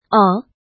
怎么读
ǒ
o3.mp3